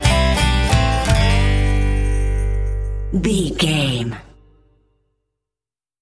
Ionian/Major
acoustic guitar
bass guitar
banjo
Pop Country
country rock
bluegrass
happy
uplifting
driving
high energy